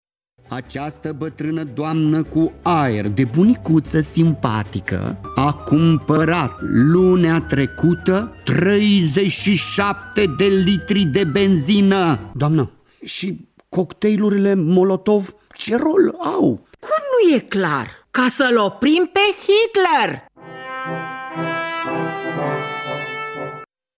O nouă piesă de teatru radiofonic, lansată la Radio Tg.Mureș
Radio Tg.Mureș lansează joi, 14 septembrie 2023,  o nouă piesă de teatru radiofonic: ”Bătrâna doamnă care fabrică 37 de cocktailuri Molotov pe zi”, după un text semnat de dramaturgul Matei Vișniec.